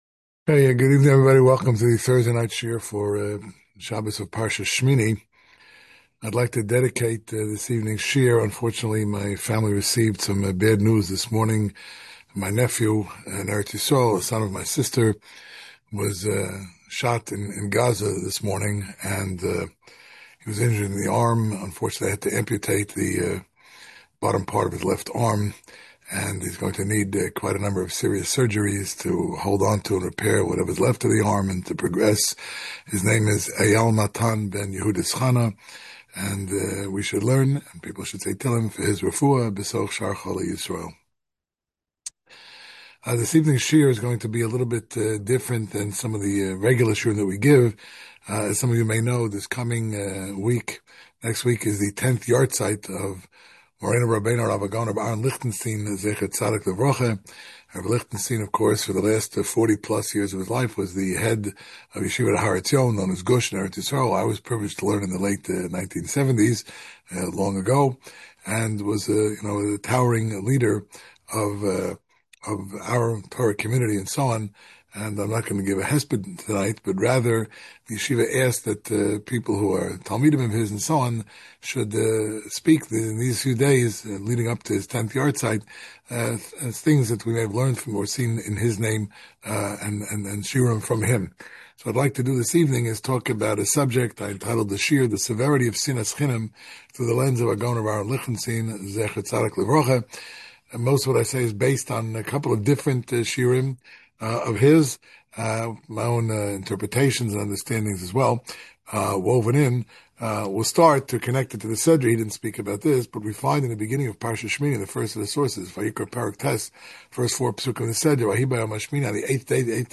Shiur given at Cong. Bnai Yeshurun (Teaneck, NJ)